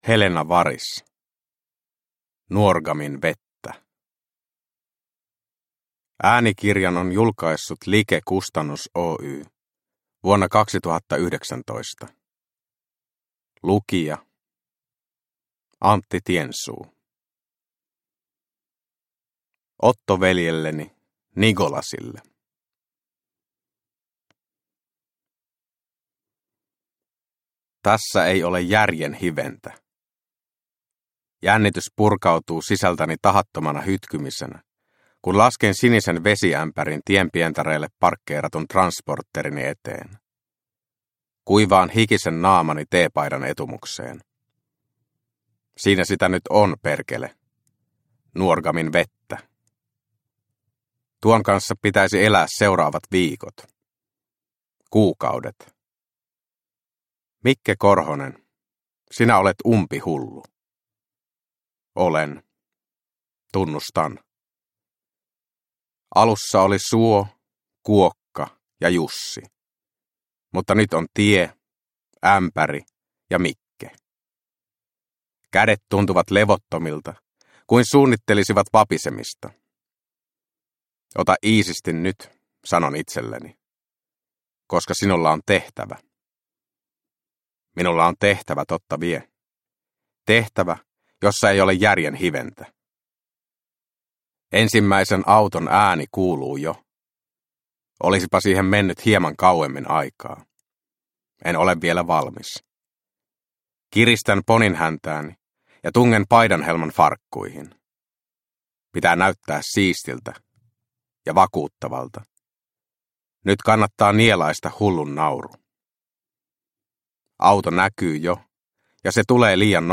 Nuorgamin vettä – Ljudbok – Laddas ner